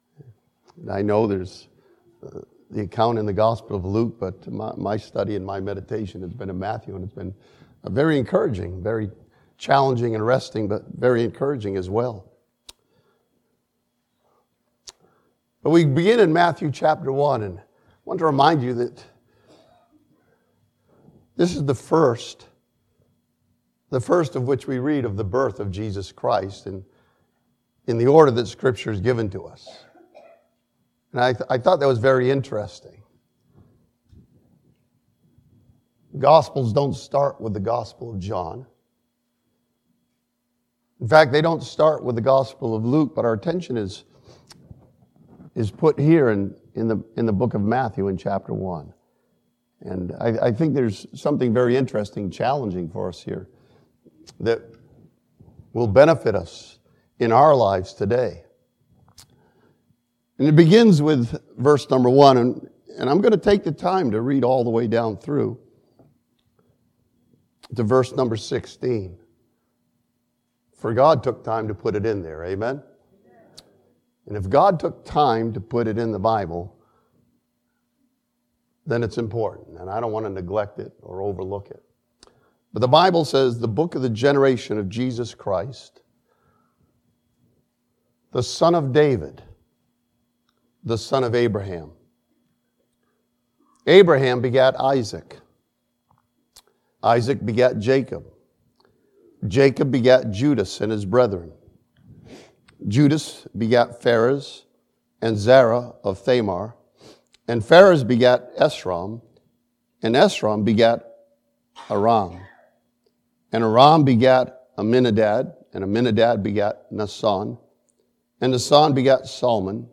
This sermon from Matthew chapter 1 studies Joseph's choice to take Mary to be his wife and name the child Jesus.